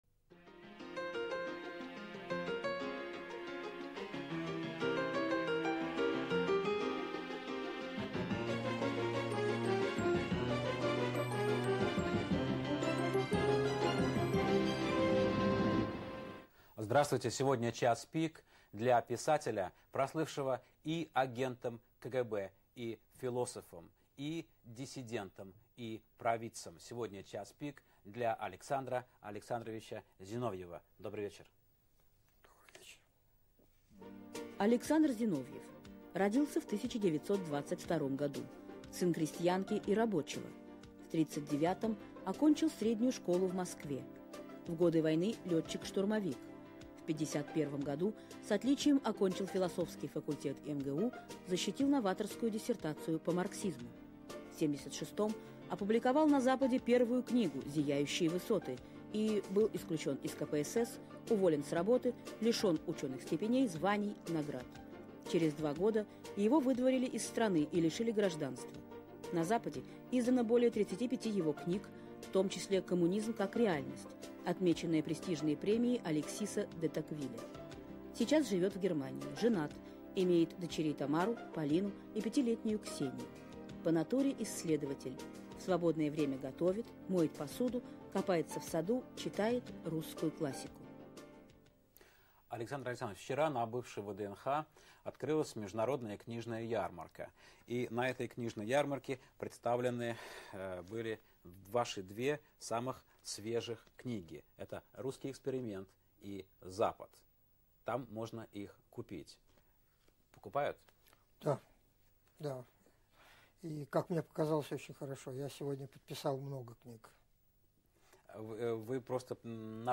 Гость программы - писатель Александр Зиновьев.